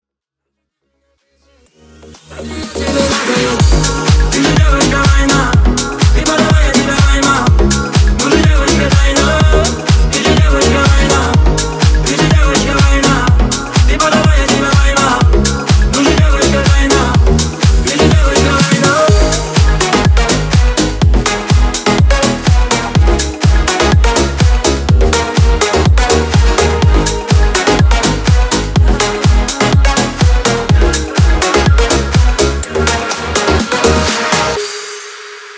• Качество: 320, Stereo
громкие
remix
зажигательные
Club House
энергичные